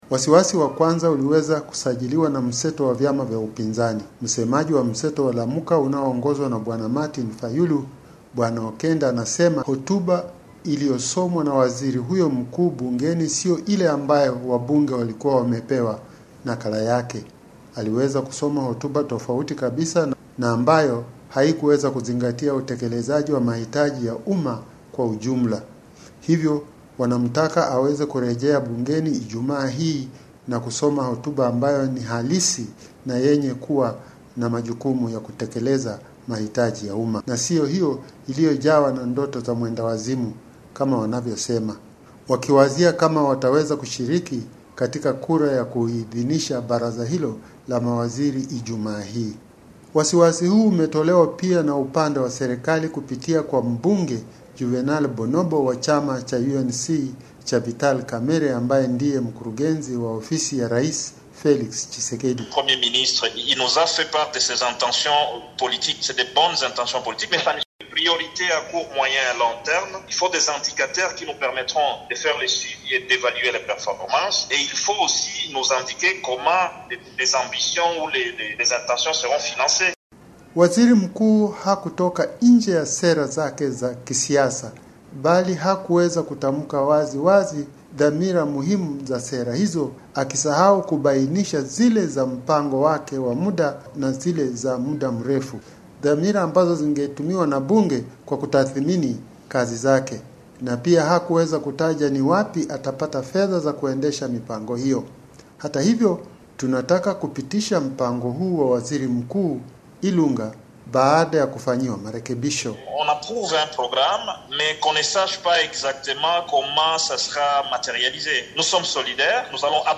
na maelezo zaidi kutoka jijini Brazzaville…